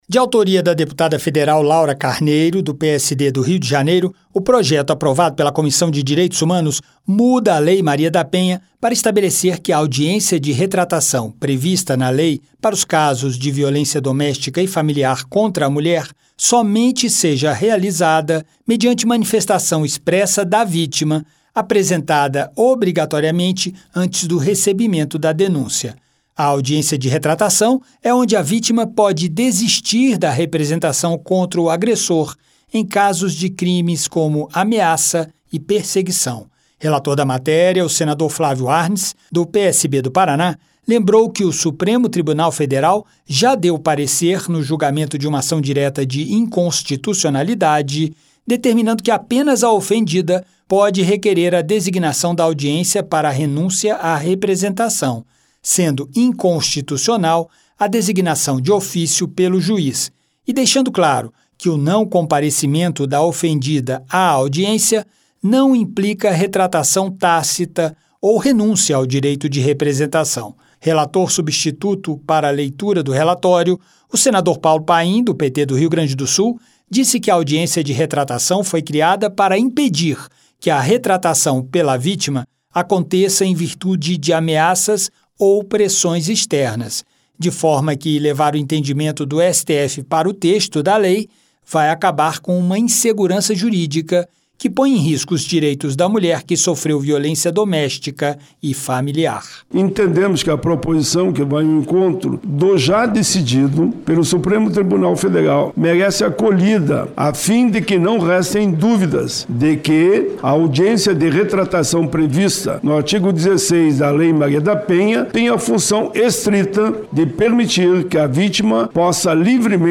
A proposta determina que a audiência de retratação, usada em situações de violência doméstica contra a mulher, só aconteça se a própria vítima pedir. O relator do projeto, senador Paulo Paim (PT-RS), explicou que a audiência serve para a vítima decidir se deseja se retratar.